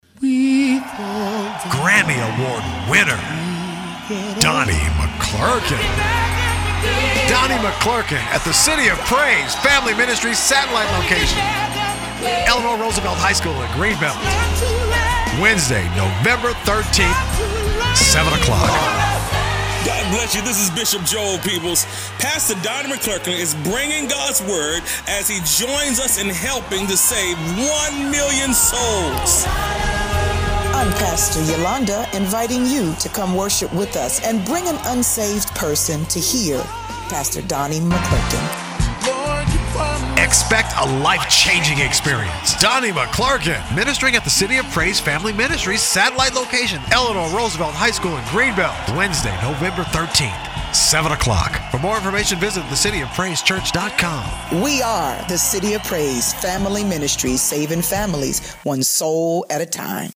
donnie-mcclurkin-city-of-praise_copfm_radiospot.mp3